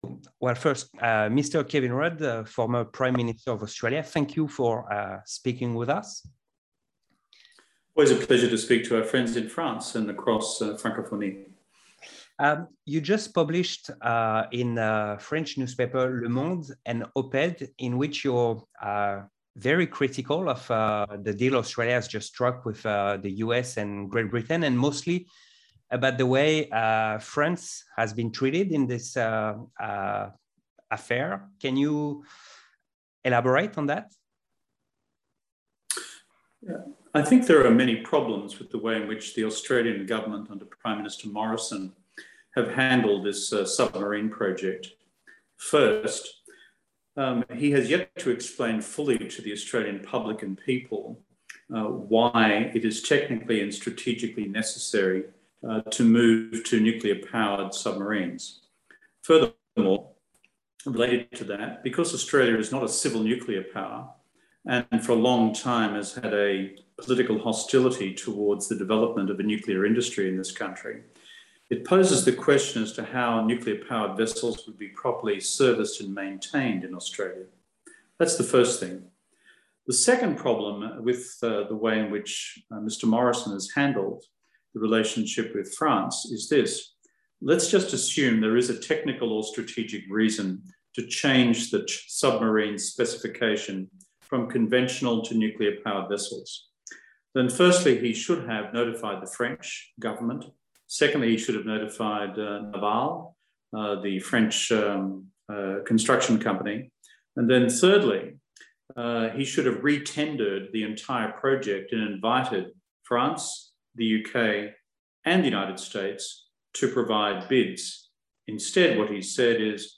Interview exclusive de l'Ancien Premier Ministre Australien Kevin Rudd et de son point de vue sur la situtation entre l'Australie et la France.